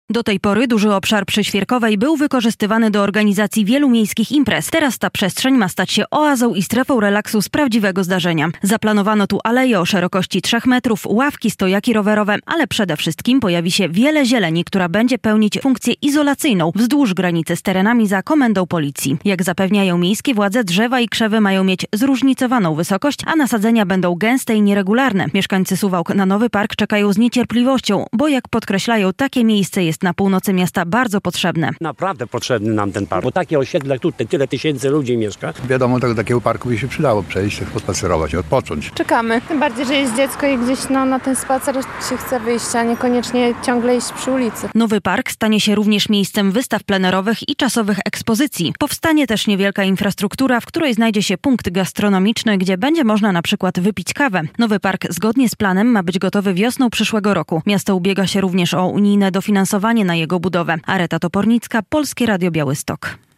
Przetarg na budowę parku w Suwałkach - relacja